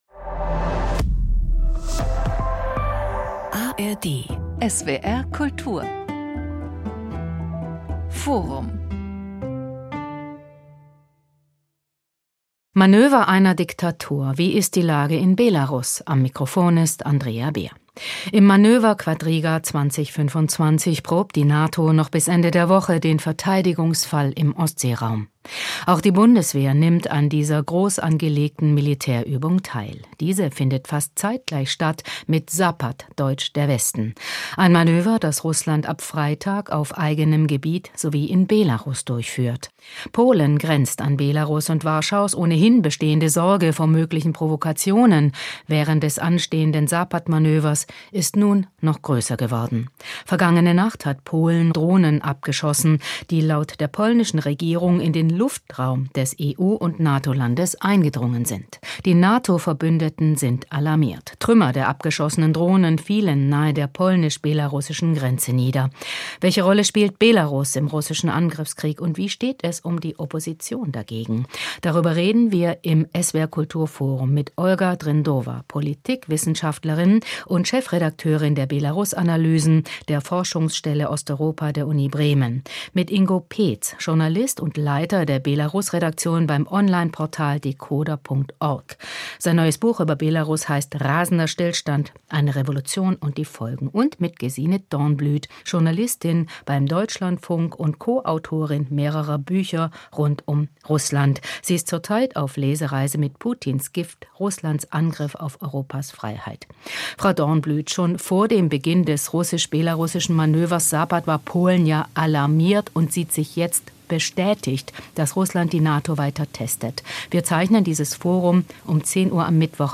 Forum